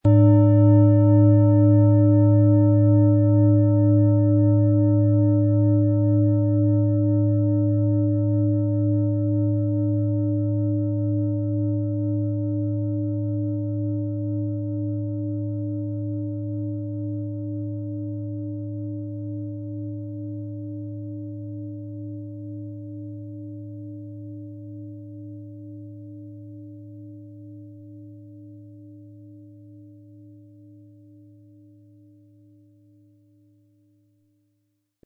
Planetenton 1
Mit einem sanften Anspiel "zaubern" Sie aus der Neptun mit dem beigelegten Klöppel harmonische Töne.
MaterialBronze